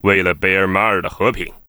文件 文件历史 文件用途 全域文件用途 Bk_fw_04.ogg （Ogg Vorbis声音文件，长度1.7秒，115 kbps，文件大小：24 KB） 源地址:游戏语音 文件历史 点击某个日期/时间查看对应时刻的文件。